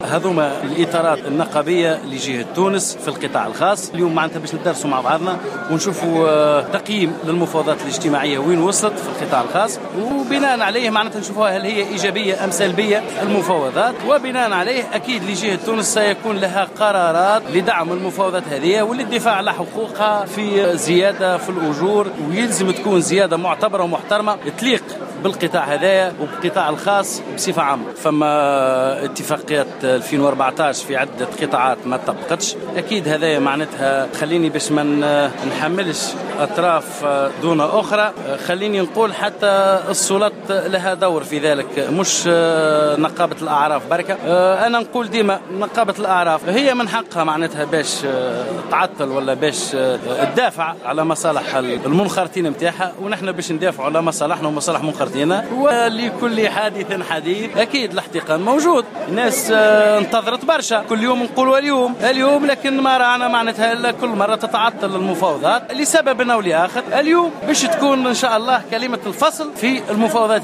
خلال اجتماع عقدته الإطارات النقابية في القطاع الخاص بجهة تونس